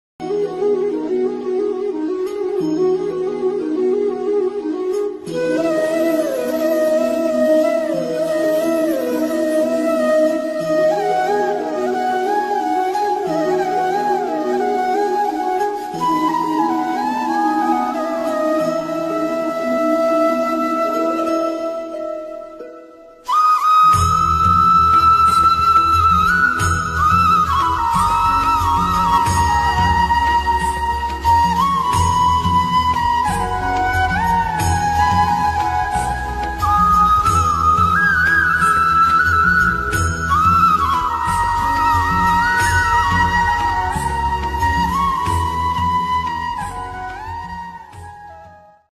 krishna bansuri dhun ringtone